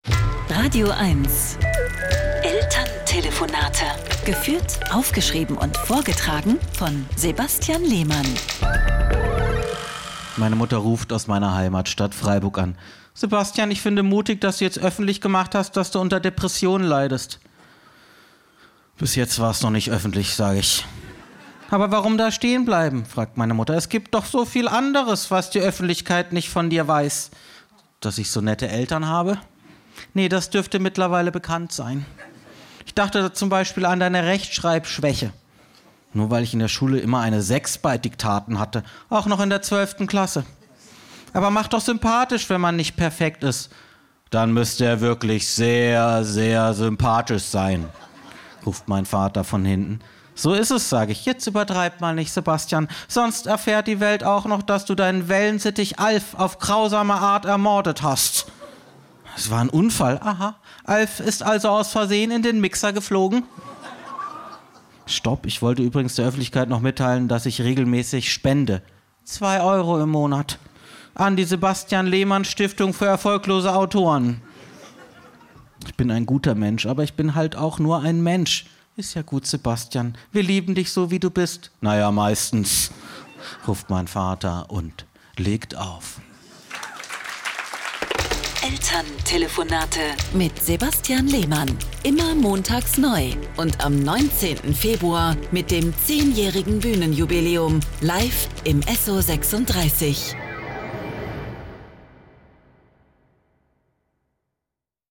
Deswegen muss er oft mit seinen Eltern in der badischen Provinz telefonieren.
Comedy